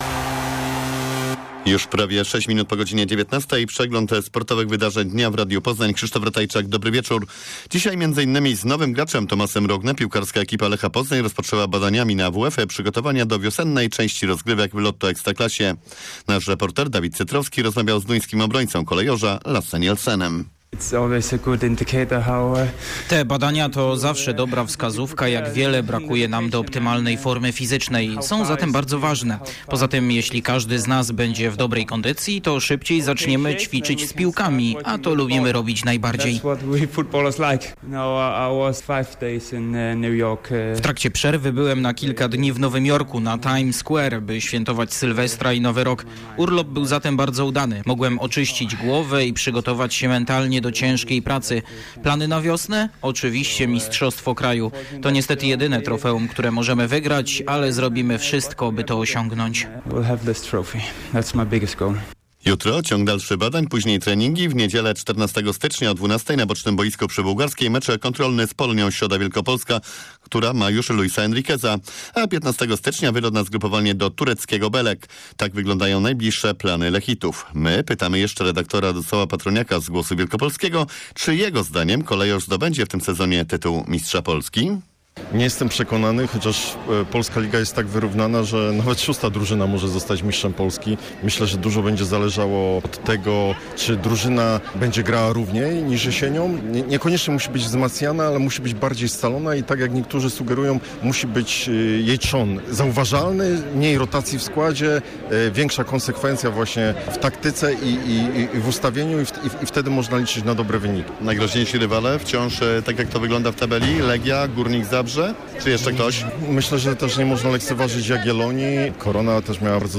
08.01 serwis sportowy godz. 19:05